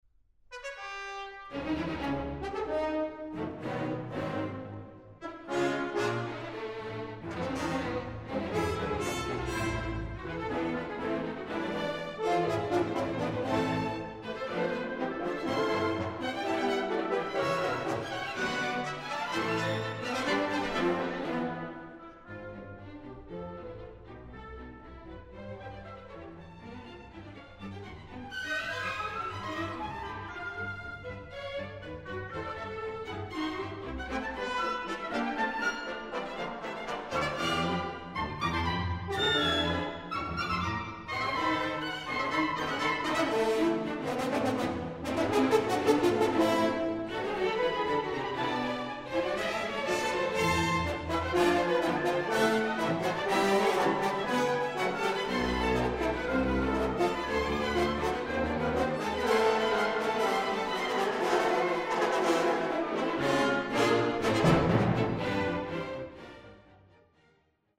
Allegro assai, sehr trotzig